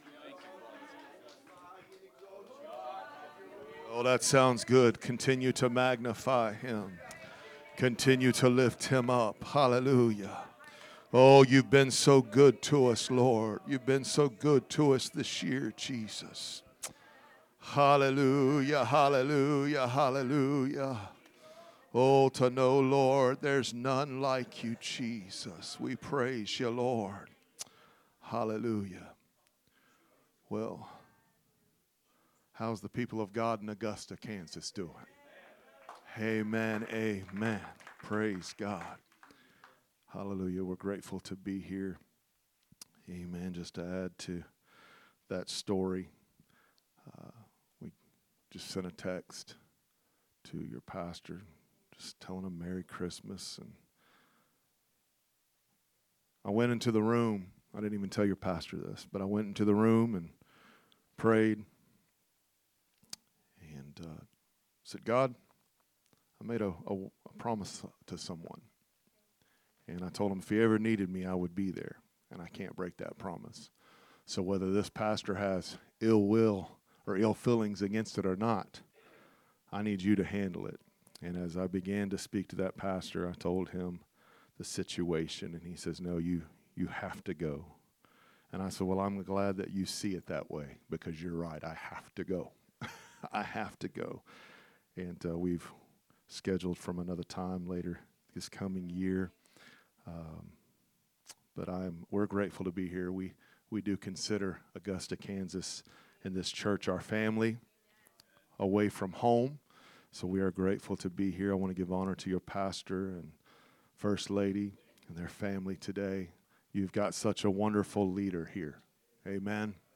A message from the series "Guest Speakers." Sunday Morning Message